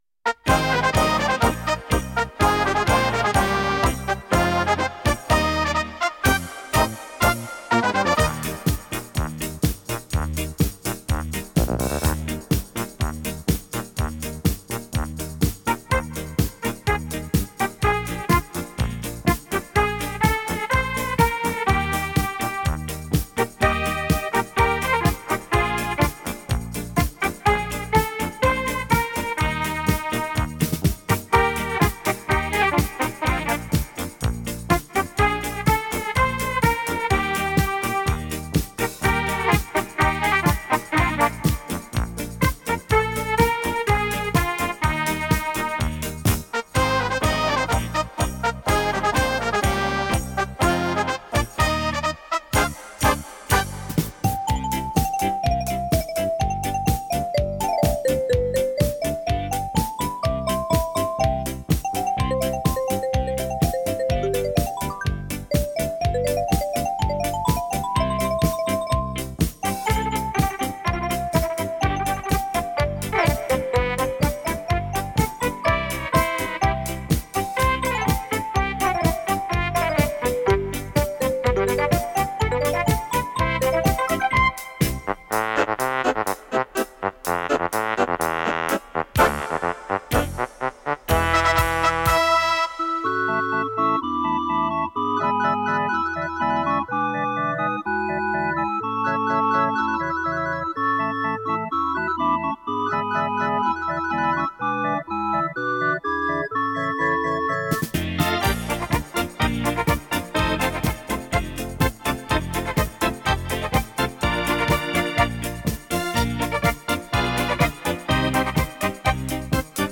略有嘶哑的声音带着古老的气味，似乎告诉我们电子琴长久的历史。